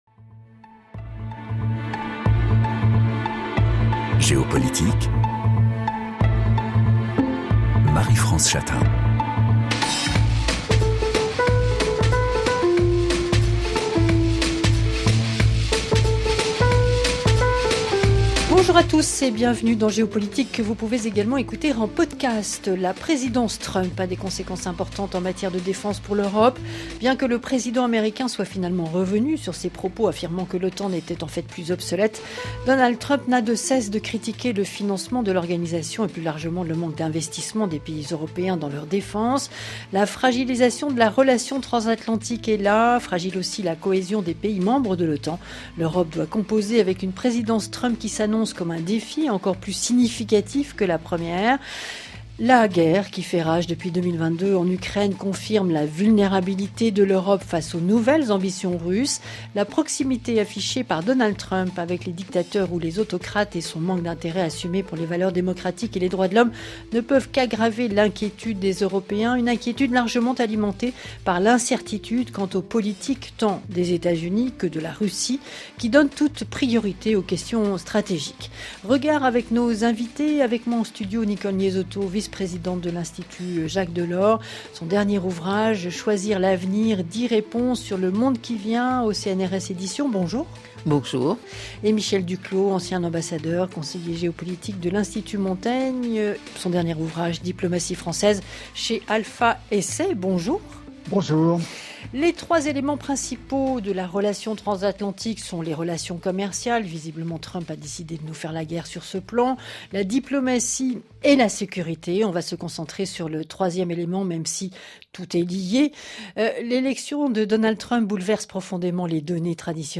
Les invités de Géopolitique confrontent leurs regards sur un sujet d’actualité internationale.